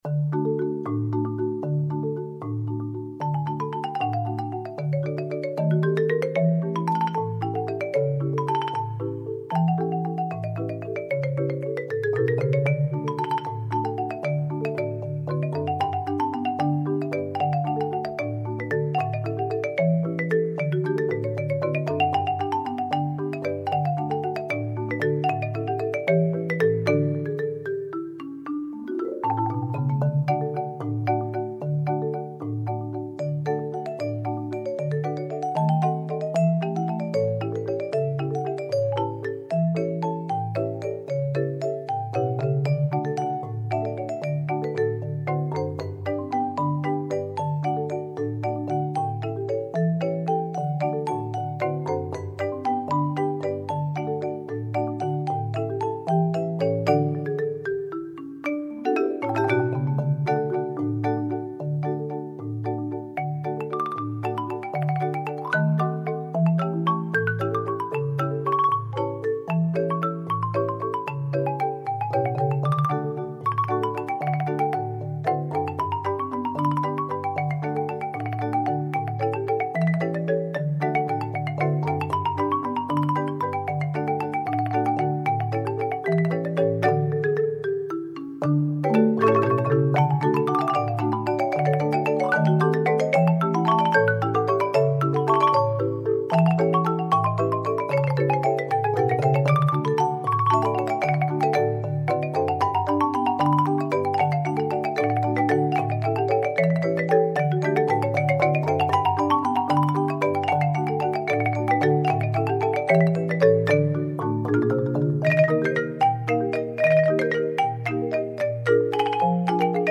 Voicing: Mallet Sextet